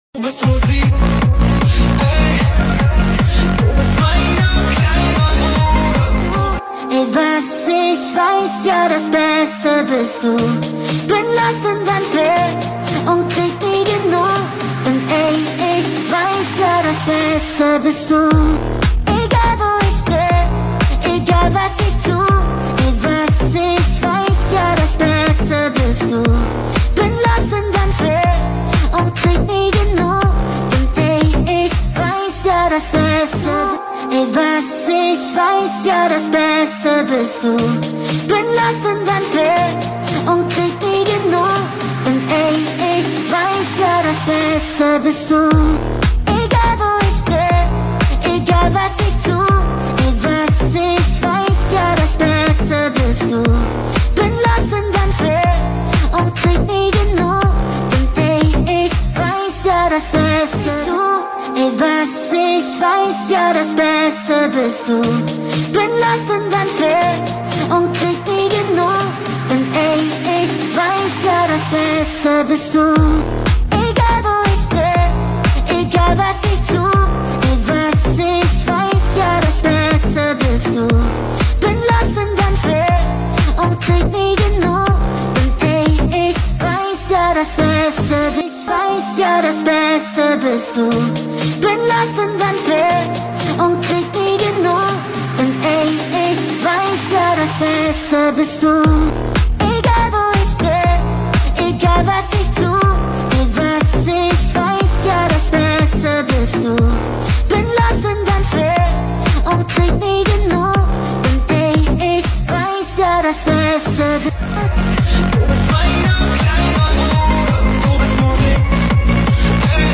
techno song